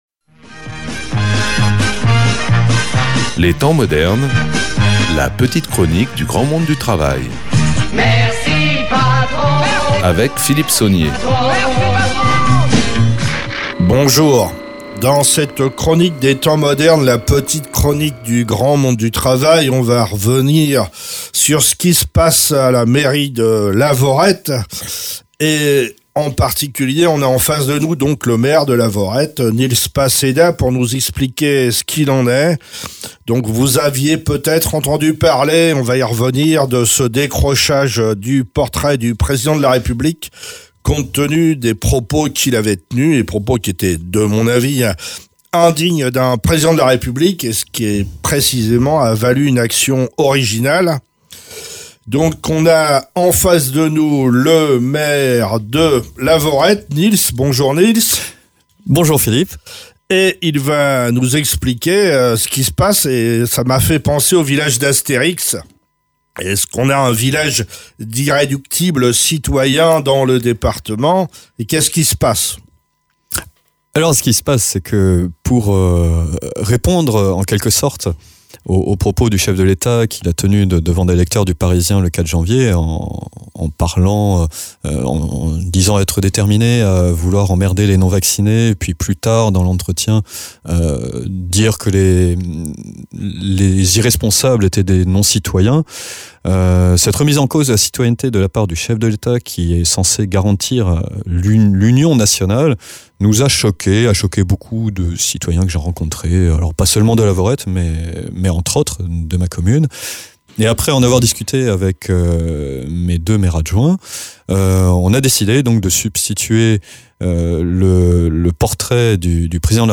Invité(s) : Nils Passedat, maire de Lavaurette